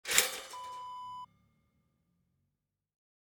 Toaster lever down sound effect .wav #2
Description: The sound of pushing a toaster lever down
Properties: 48.000 kHz 24-bit Stereo
A beep sound is embedded in the audio preview file but it is not present in the high resolution downloadable wav file.
toaster-lever-down-preview-2.mp3